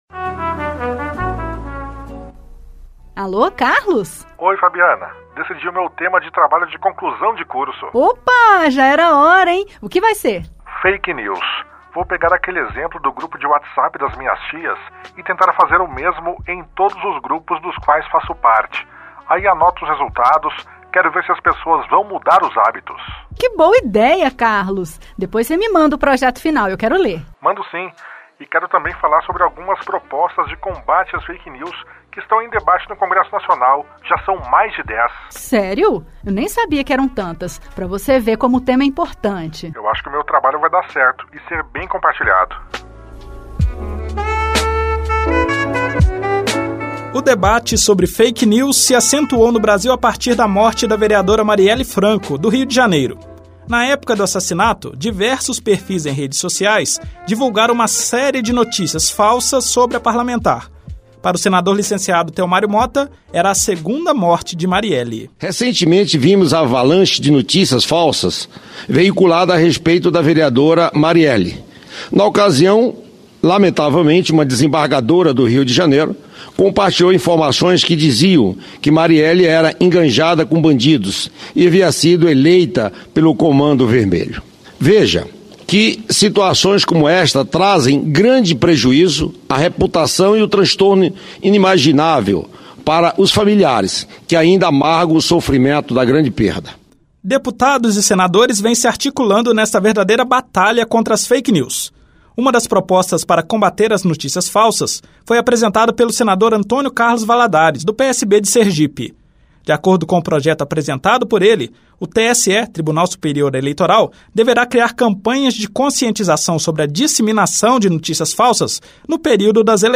Com a proximidade das eleições, ministros, especialistas e senadores debatem sobre as Fake News e como elas podem influenciar no voto dos cidadãos.
Reportagem premiada Rádio Senado